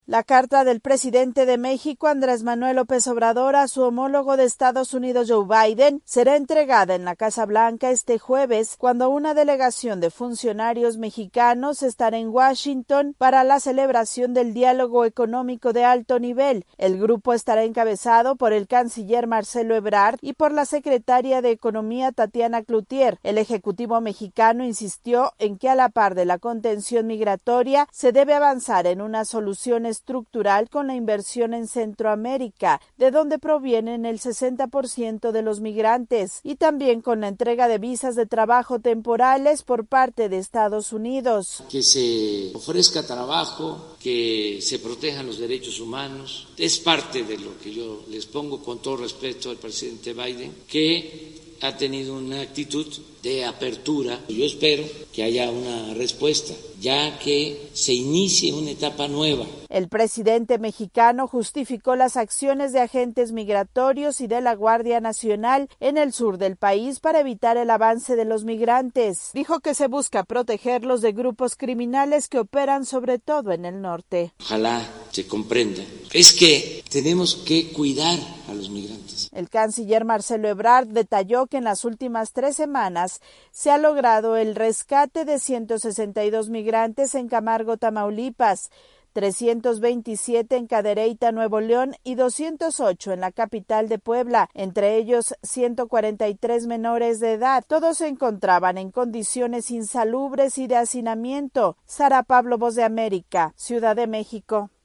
El presidente de México Andrés Manuel López Obrador confió en que su homólogo de Estados Unidos, Joe Biden, atienda su propuesta y se inicie una etapa nueva en materia migratoria. Desde Ciudad de México informa la corresponsal de la Voz de América